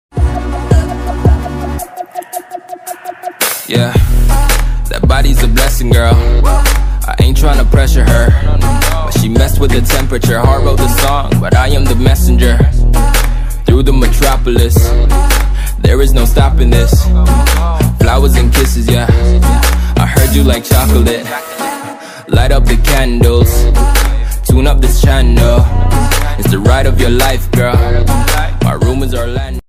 Category: BGM